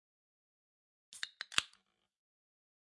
苏打水和啤酒罐 " 开罐02
描述：使用Zoom H1和Rode Videomic录制
Tag: 苏打 罐头 饮料 啤酒 金属物体 饮料